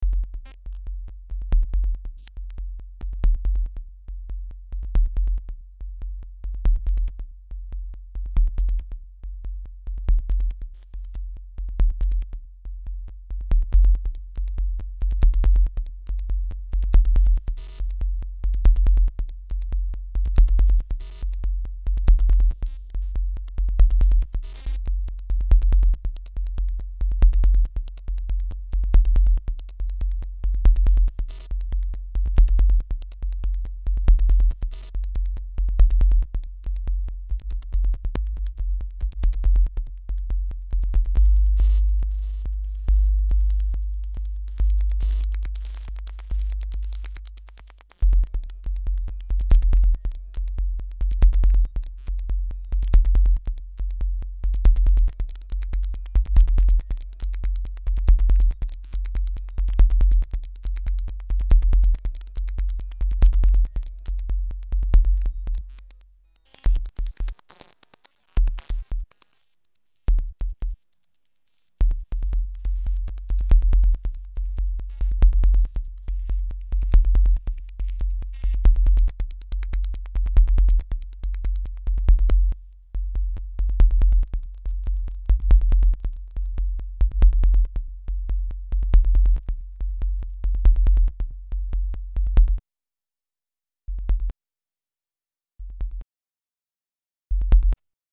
低い周波数の音が入ってるので、ヘッドフォンで聴いてください（パソコン本体備え付けのスピーカーとかだと伝わりにくいです…）。